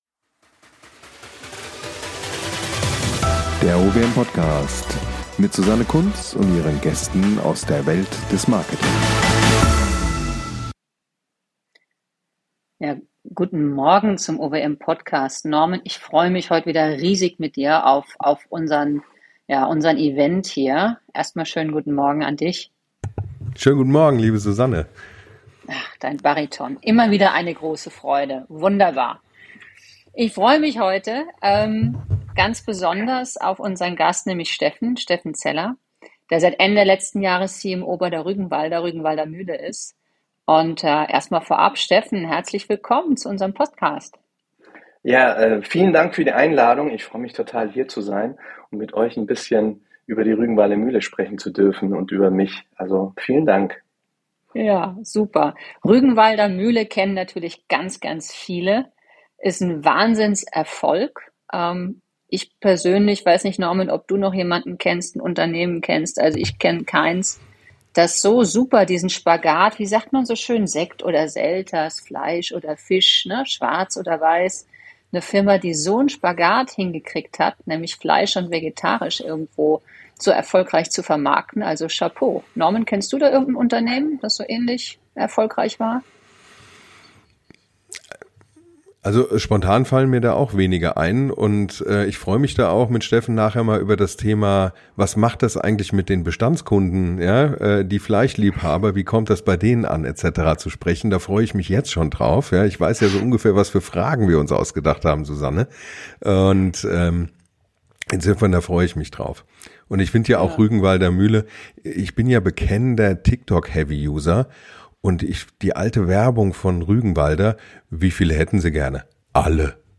im Gespräch mit der OWM ~ Der OWM Podcast